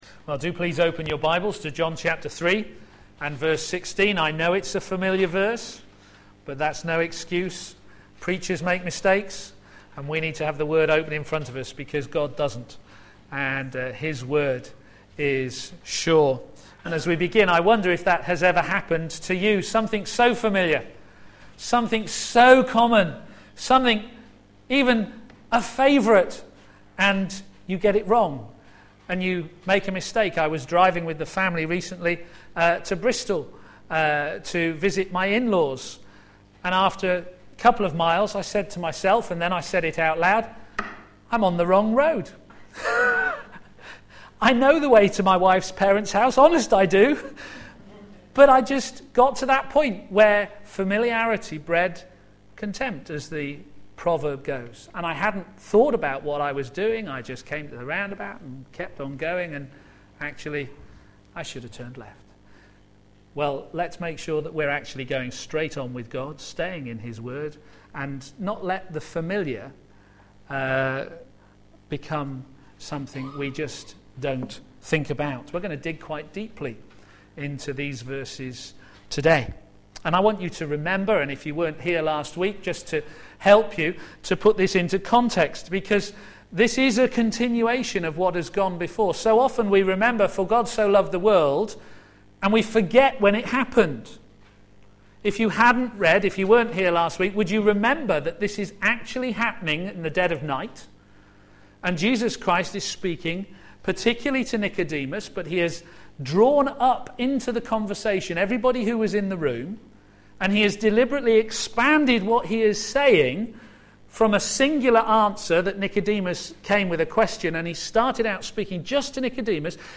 a.m. Service
Series: John on Jesus Theme: For God so Loved the World Sermon